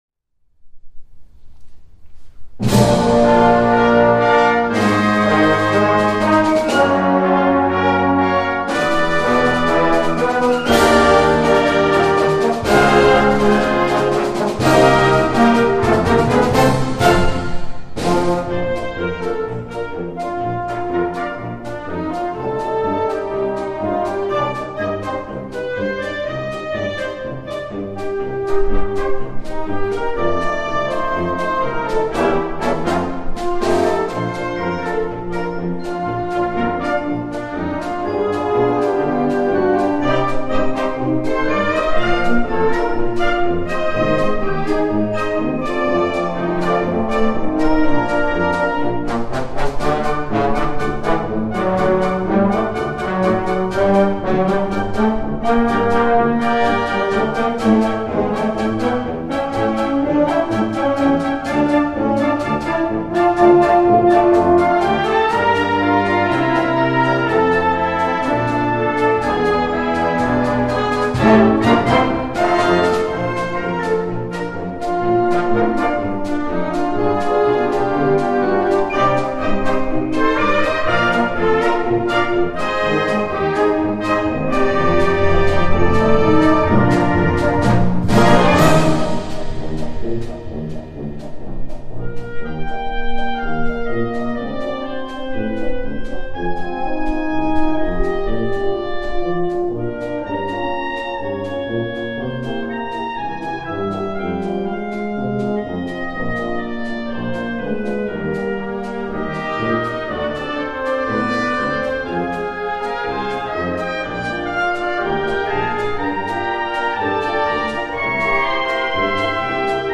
※同校吹奏楽部のご厚意により、定期演奏会当日の演奏音源をお聴きいただけます。